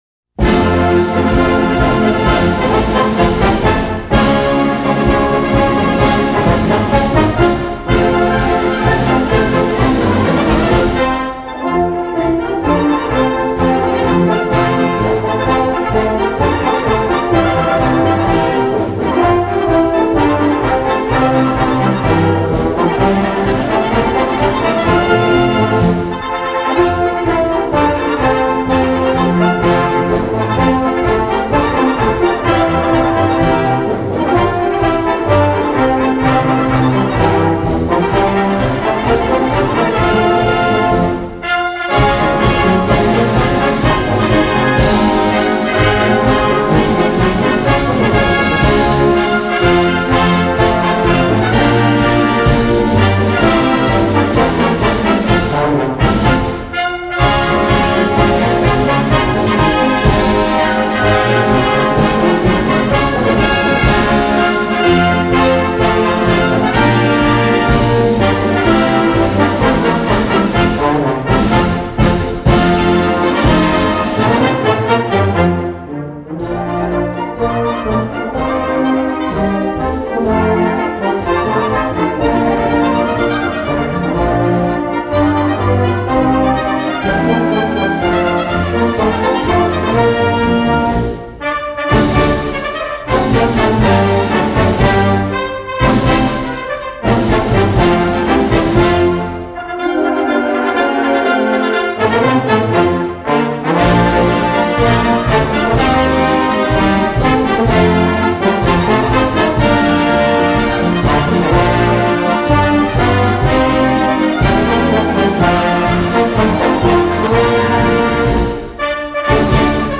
Voicing: Concert March